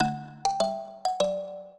minuet0-3.wav